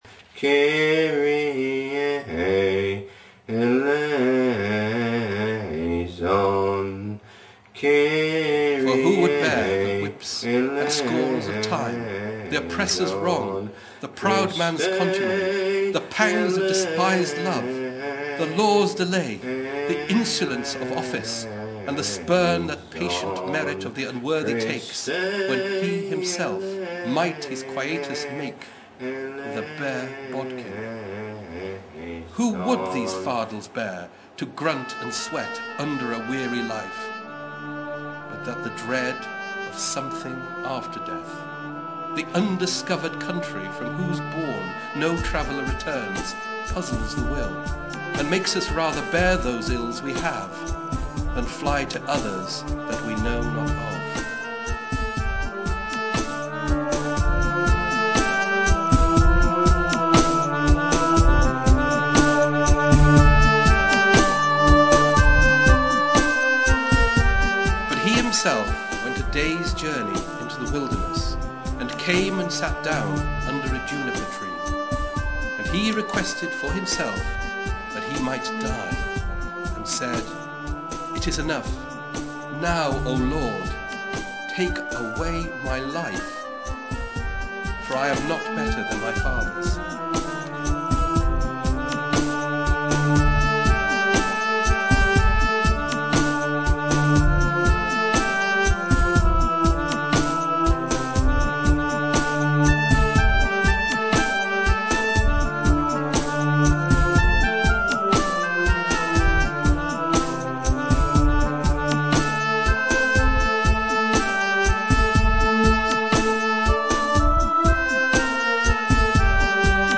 From the Soundtrack (MP3)